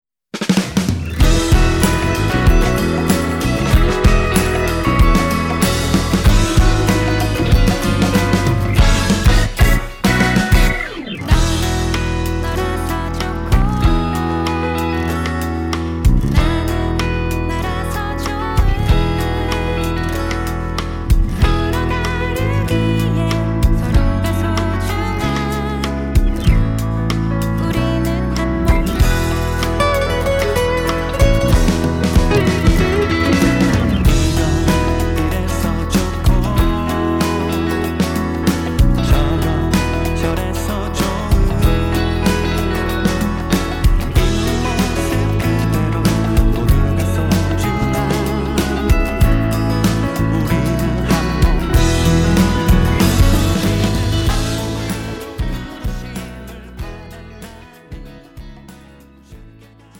음정 원키
장르 가요 구분
가사 목소리 10프로 포함된 음원입니다